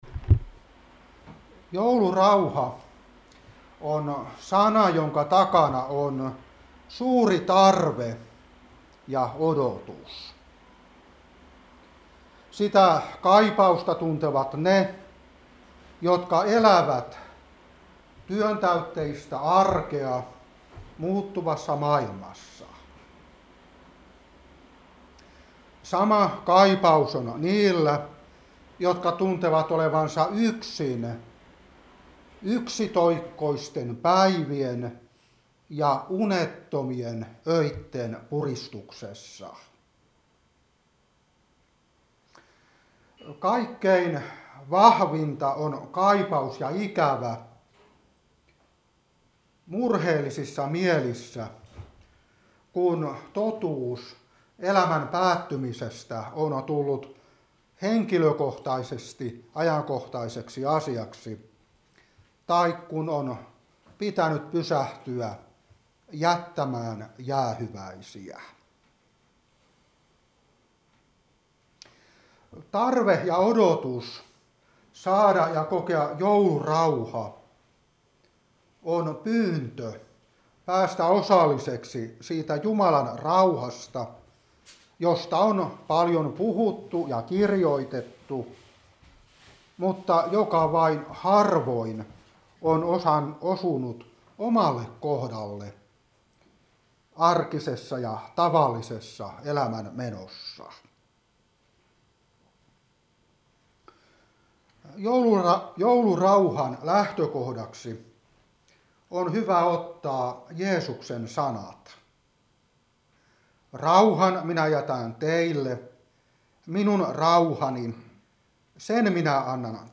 Seurapuhe 2023-12.